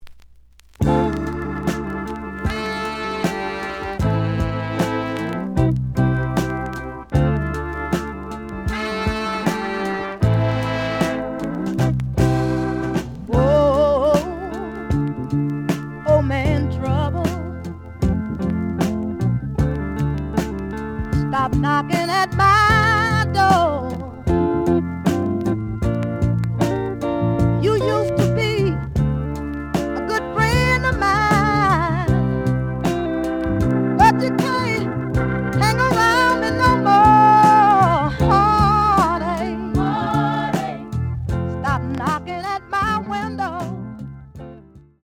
The audio sample is recorded from the actual item.
●Genre: Soul, 70's Soul
B side plays good.)